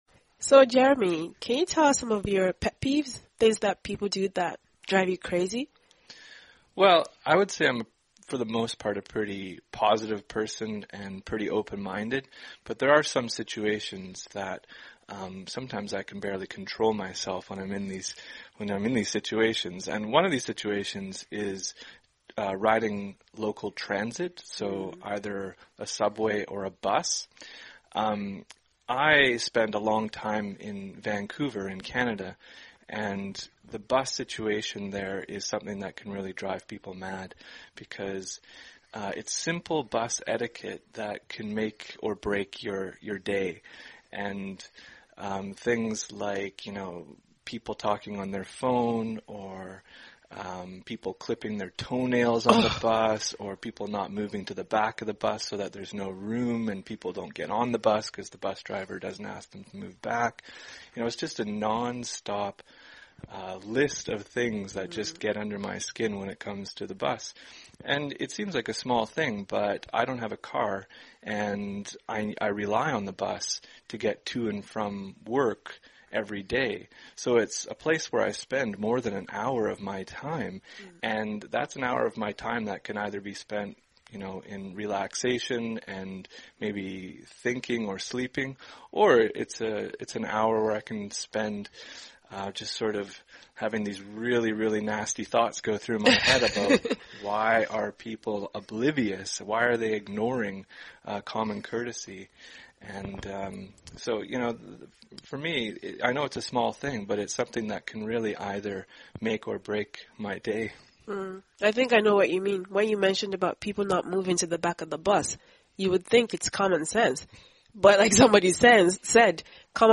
实战口语情景对话 第1065期:Pet Peeves on the Bus 公交车上令人无法忍受的事